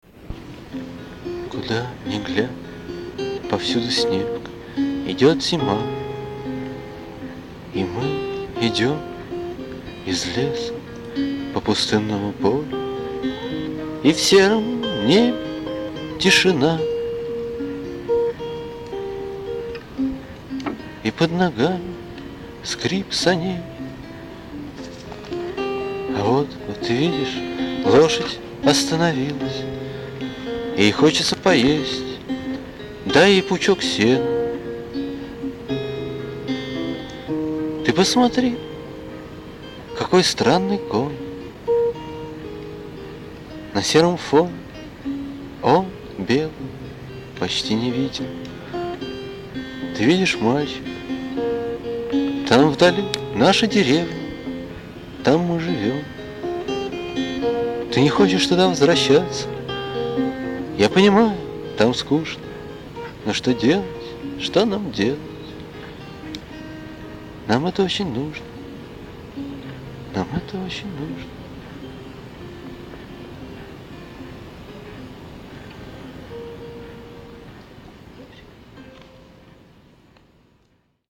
вокал
бэк-вокал
гитара     Обложка